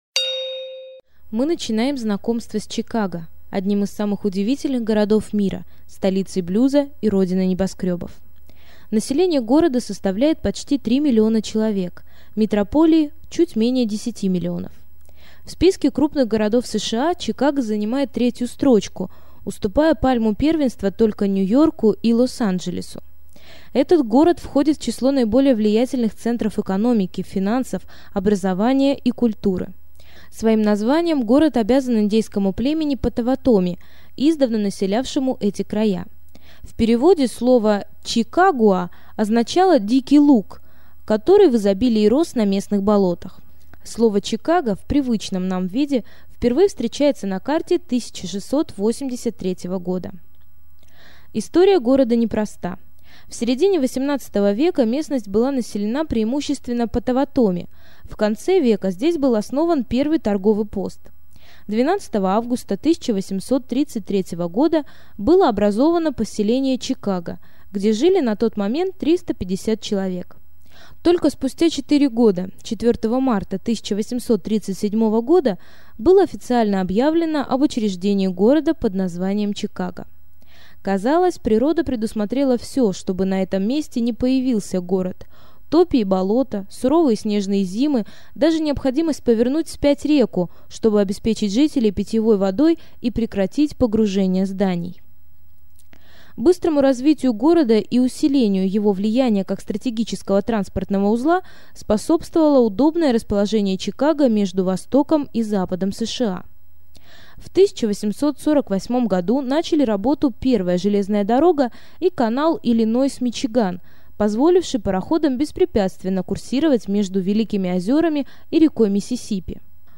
Аудиокнига Чикаго | Библиотека аудиокниг
Прослушать и бесплатно скачать фрагмент аудиокниги